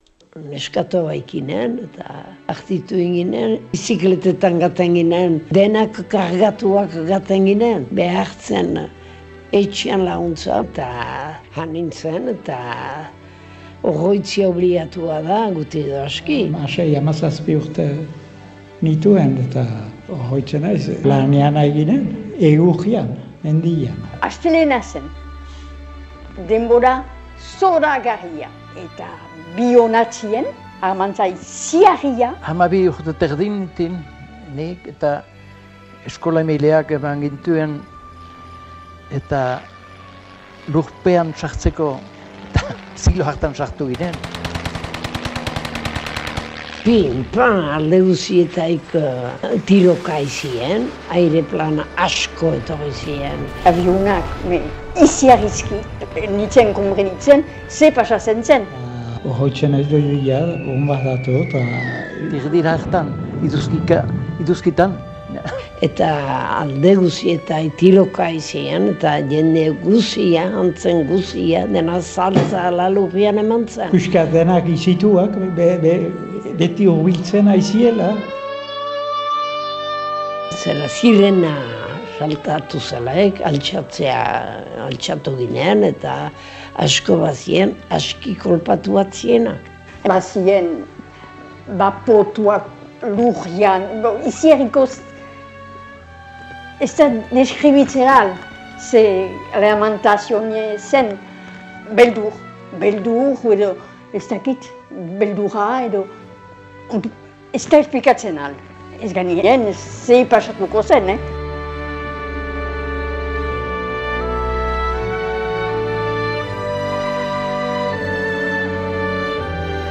Biarritz eta Angeluko bonbaketaren 70garren urteurreneko lekukotza jaso ditugu Faktorian.